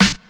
• Clean Rap Snare Drum F# Key 233.wav
Royality free snare drum tuned to the F# note. Loudest frequency: 2135Hz
clean-rap-snare-drum-f-sharp-key-233-1mp.wav